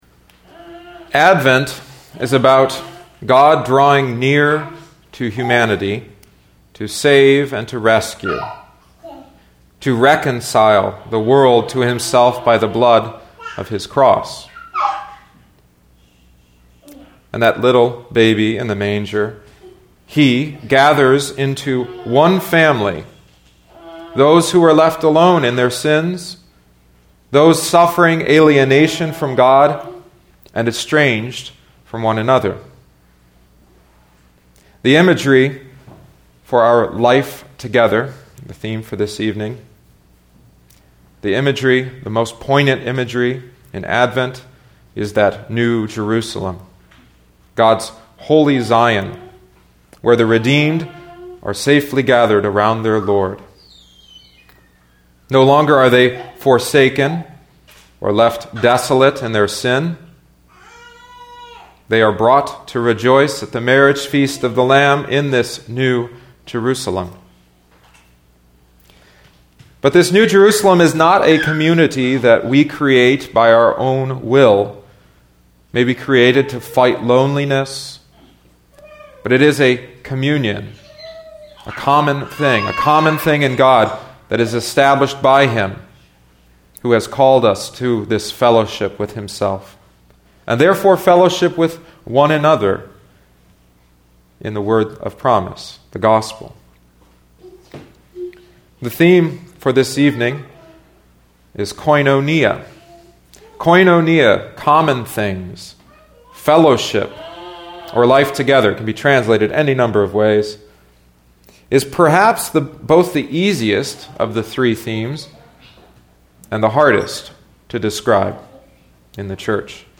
A preaching series based on and drawn from John Pless’s outline and Al Collver’s Bible study materials.